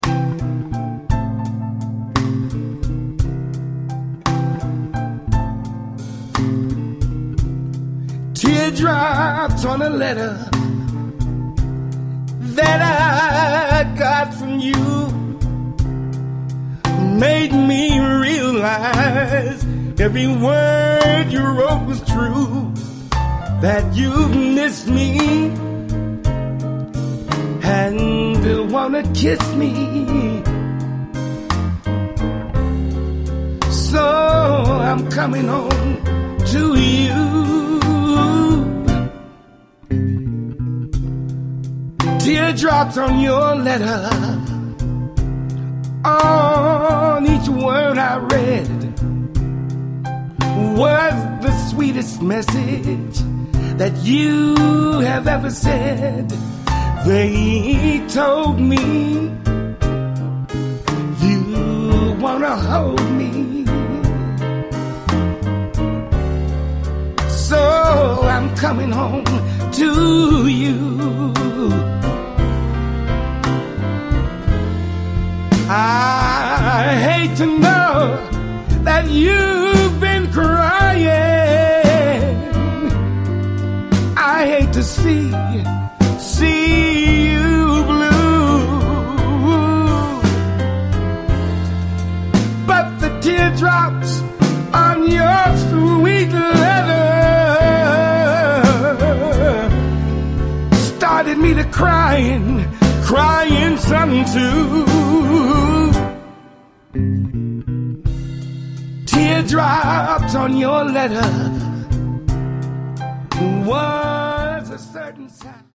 to Memphis-style R and B